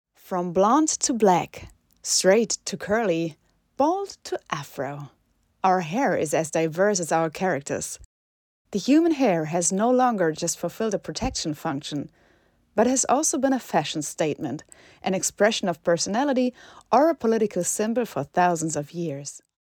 markant
Jung (18-30)
Imagefilm: englischsprachiges Demo